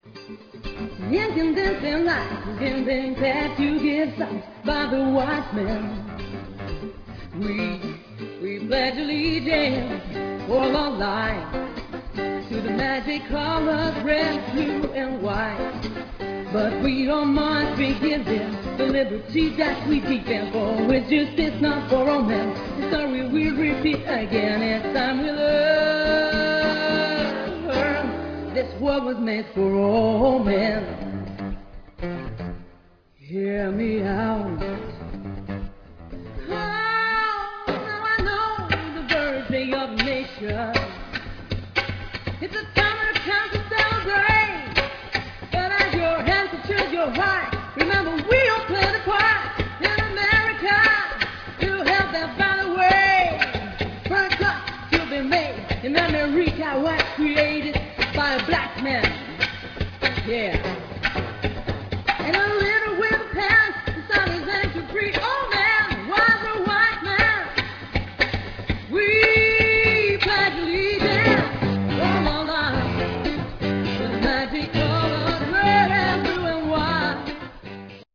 4. live